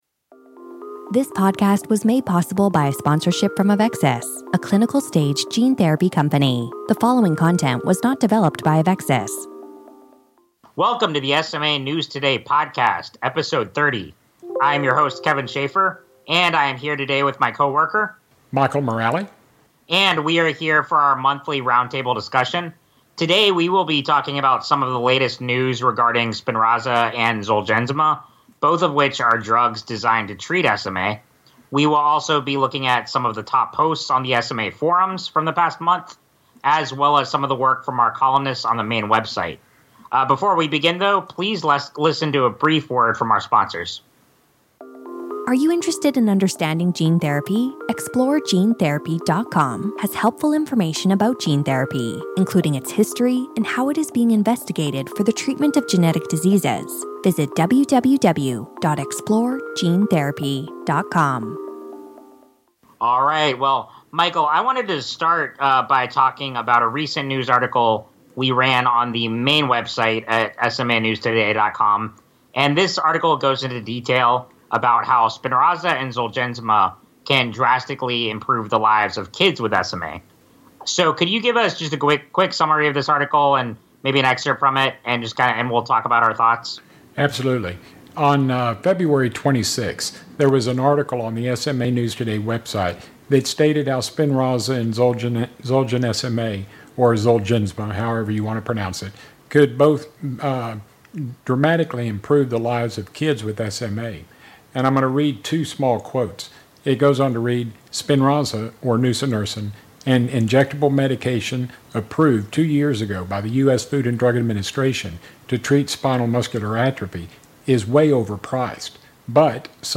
#30 - Roundtable Discussion - Featured Articles